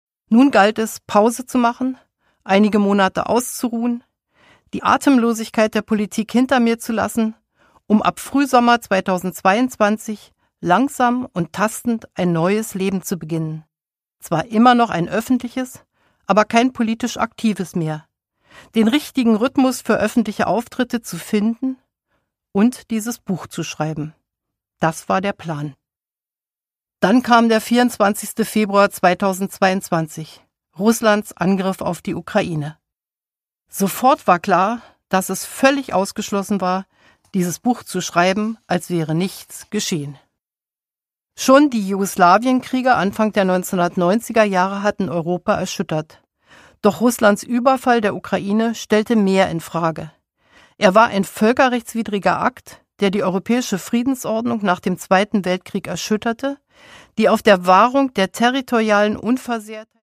Produkttyp: Hörbuch-Download
Gelesen von: Corinna Harfouch, Angela Merkel